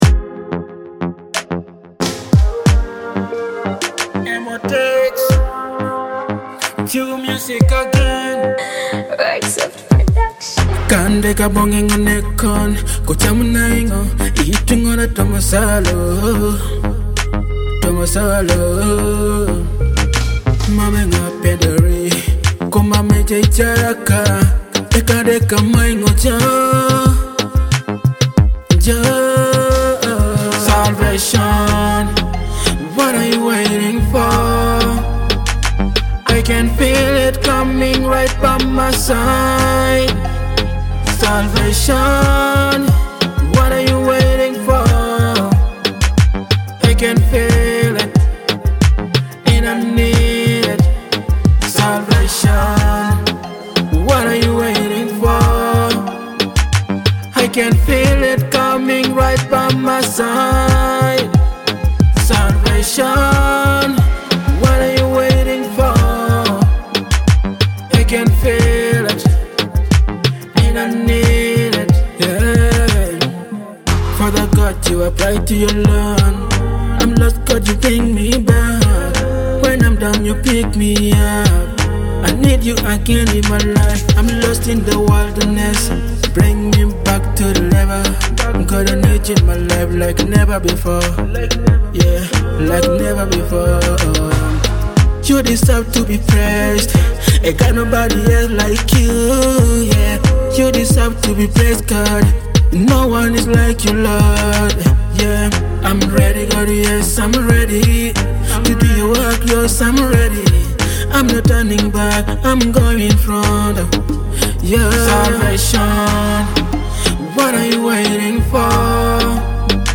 a fresh Teso gospel hit
enjoy authentic regional sound at its best